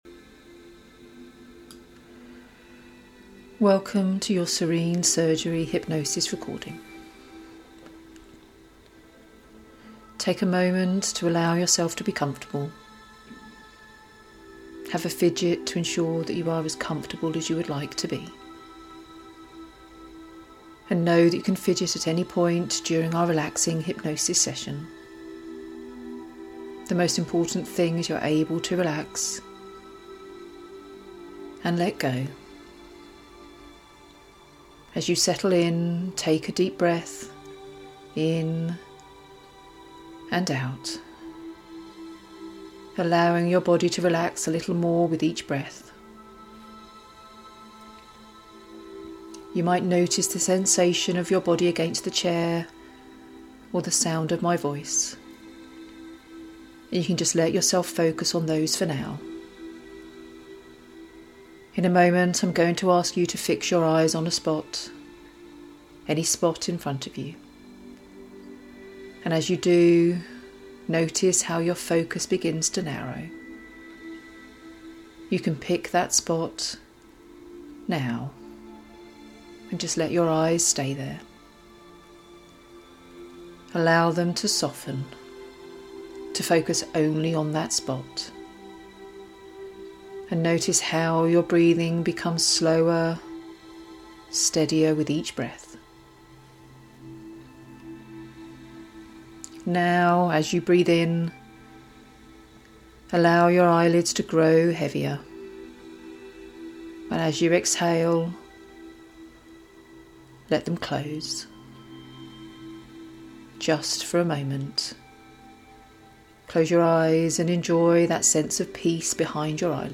Pre-Surgery Calm: Guided Self-Hypnosis
Preview-Pre-Surgery-Calm-Hypnosis.mp3